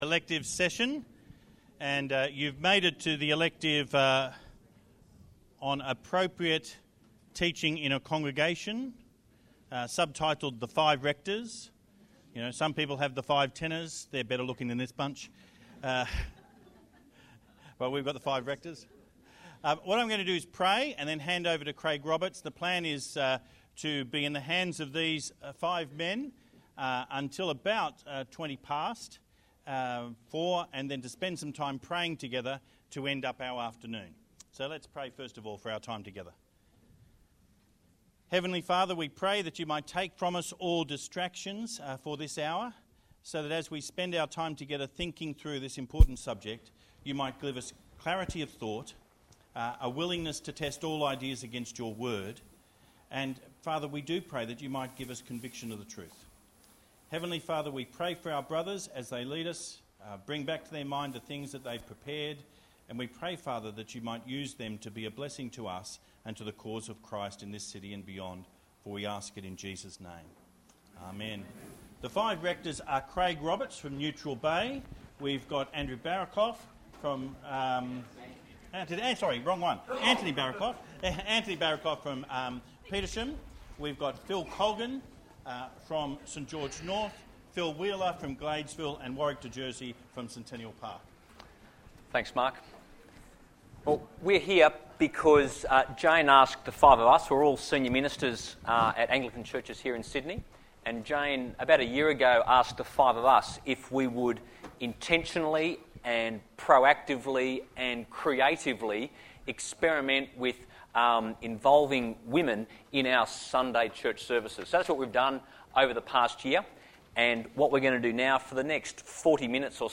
Elective at the 2012 Priscilla & Aquila Centre conference.
Note: the recording of this varies at times.